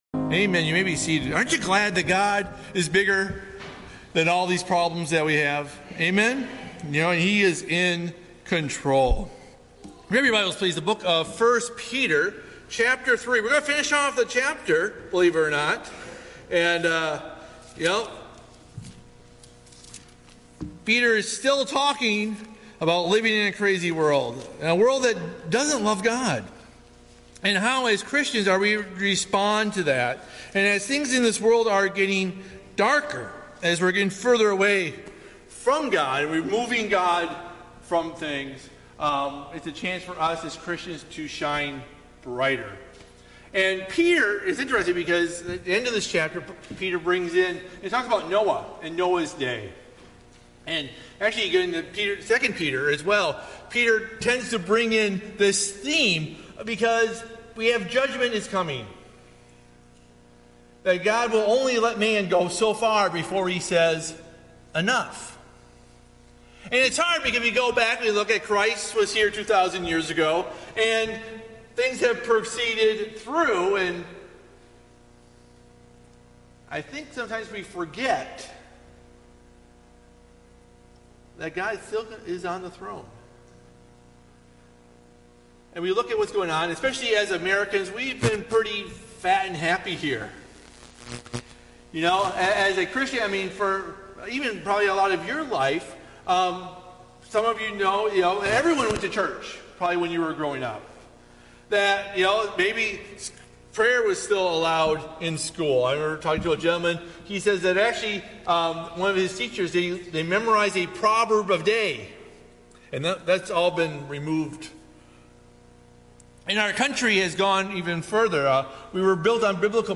Bible Text: 1 Peter 3:15-22 | Preacher